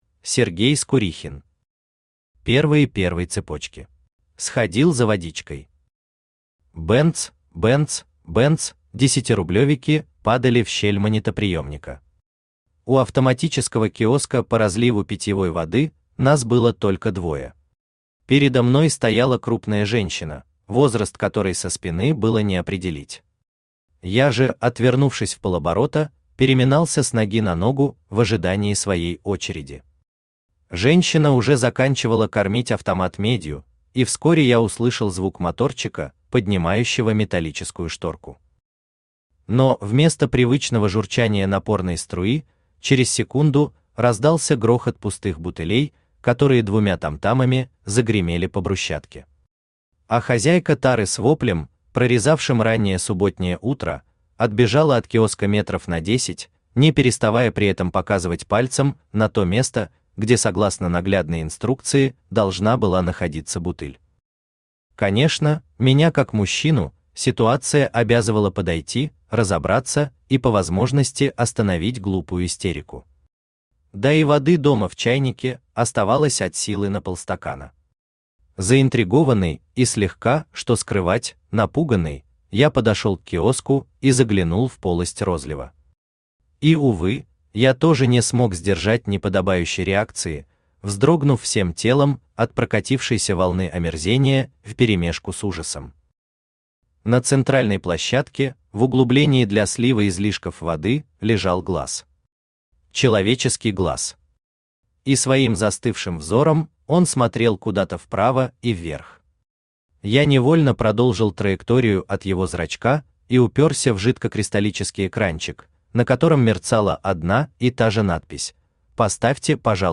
Аудиокнига Первые первой цепочки | Библиотека аудиокниг
Aудиокнига Первые первой цепочки Автор Сергей Леонидович Скурихин Читает аудиокнигу Авточтец ЛитРес.